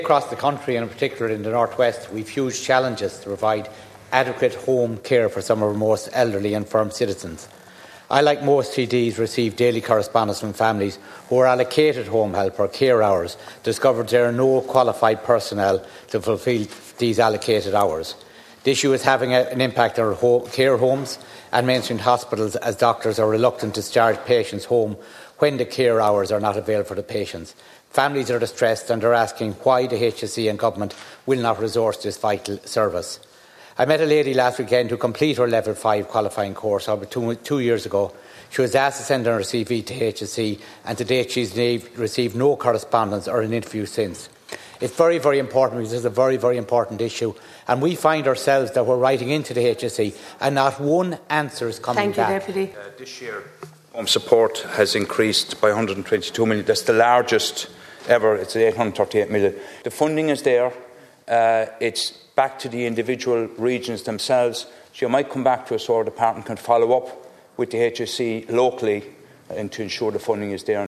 Minister Kieran O’Donnell was responding to Sligo Leitrim and South Donegal TD Frank Feighan, who raised the issue in the Dail……